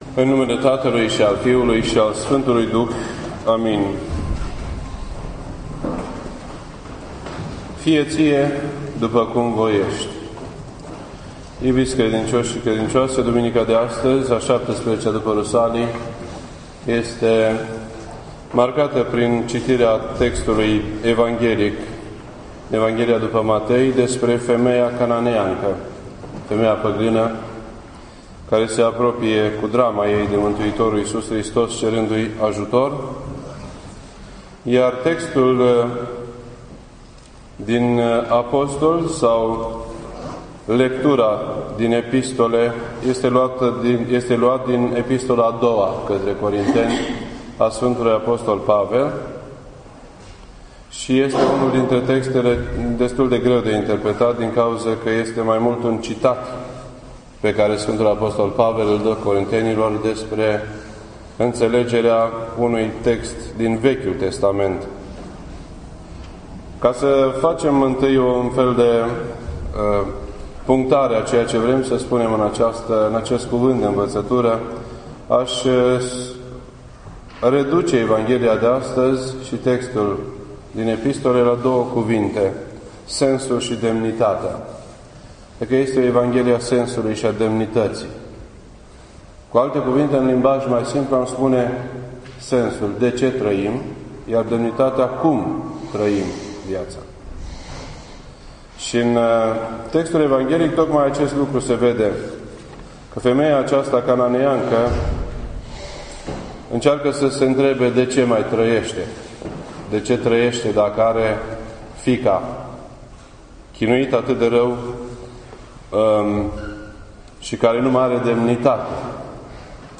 This entry was posted on Sunday, February 17th, 2013 at 8:17 PM and is filed under Predici ortodoxe in format audio.